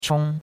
chong1.mp3